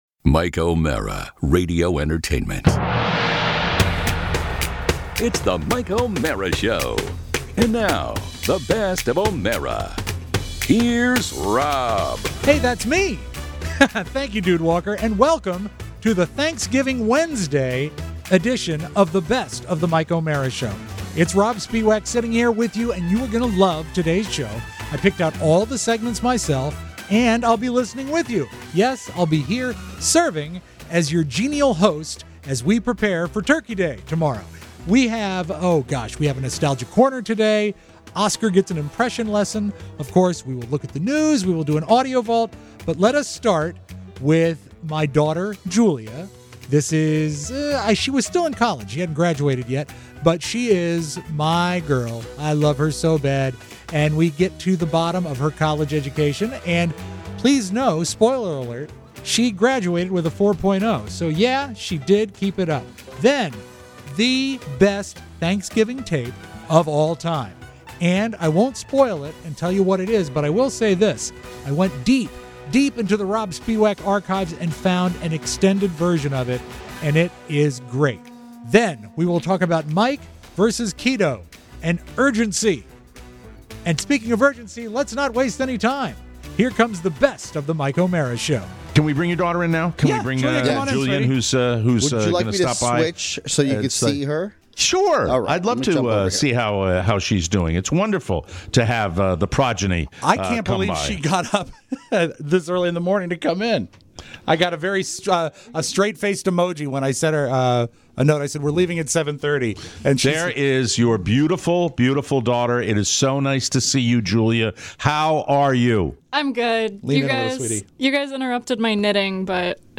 It has impressions, family members, nostalgia, food... and the greatest Thanksgiving tape of them all.